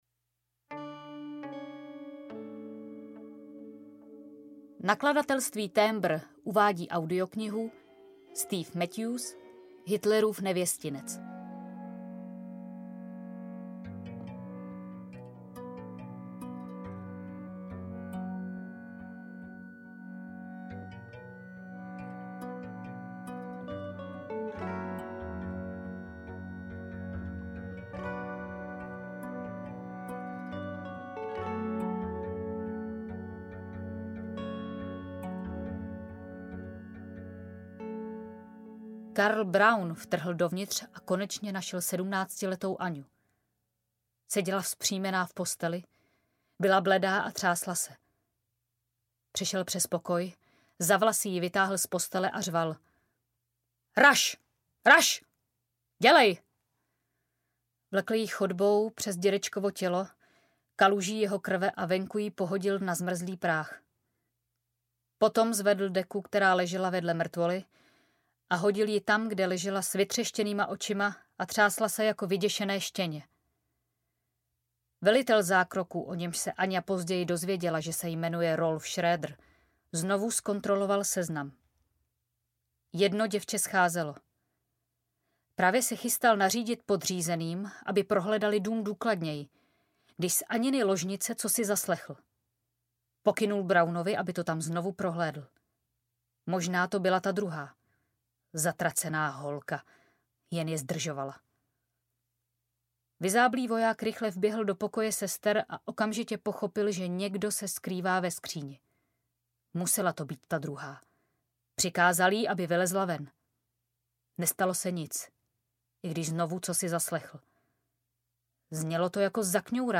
Hitlerův nevěstinec audiokniha
Ukázka z knihy
hitleruv-nevestinec-audiokniha